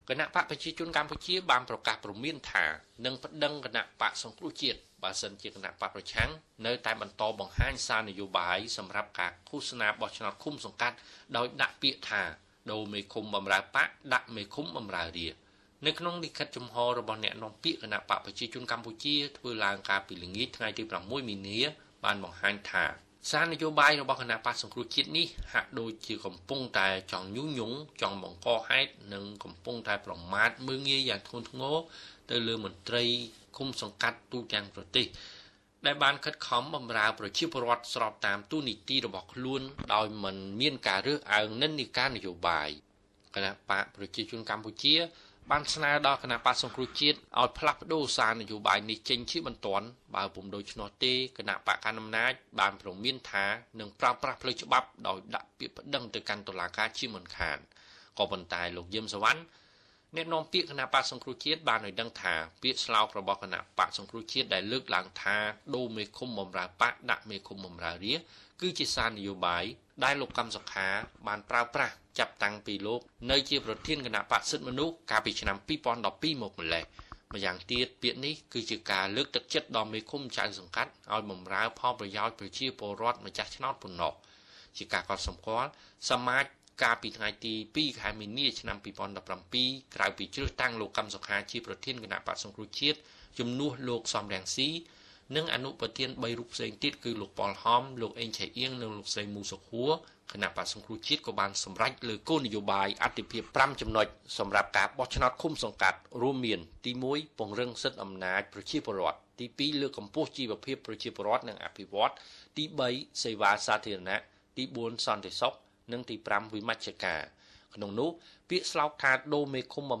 រាយការណ៍លំអិត។